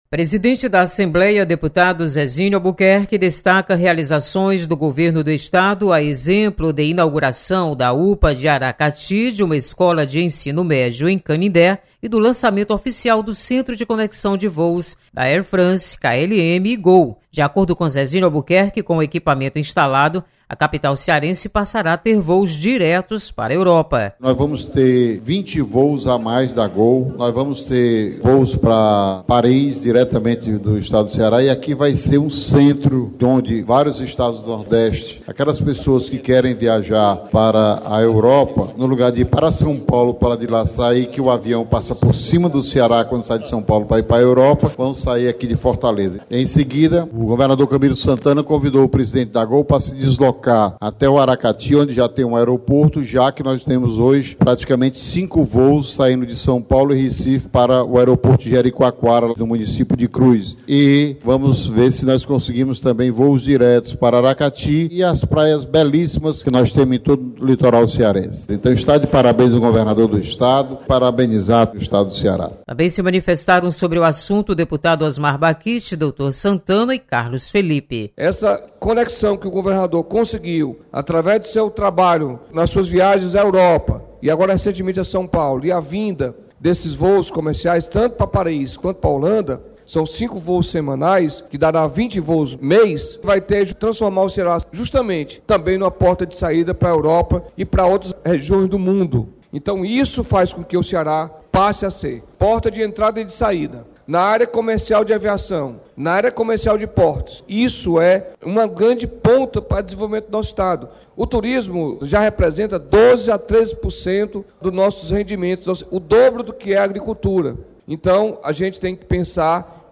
Deputados destacam importância do Hub Air France-KLM-Gol. Repórter